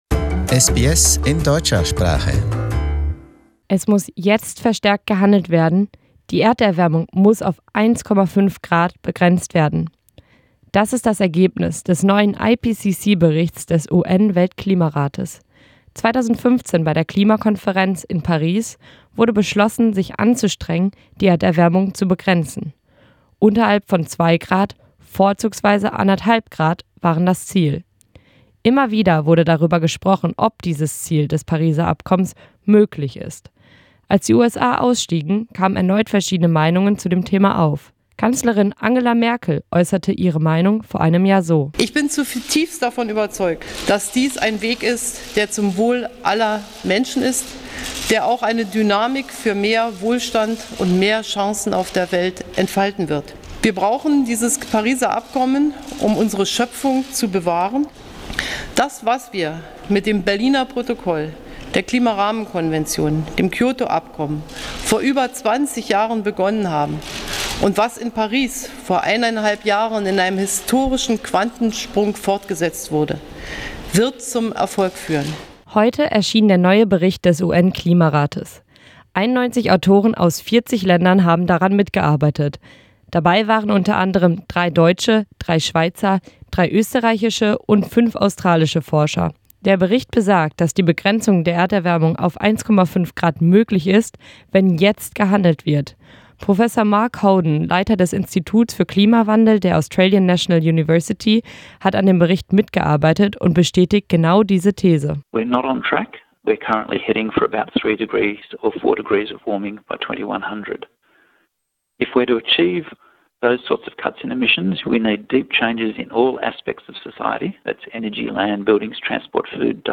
SBS-Interview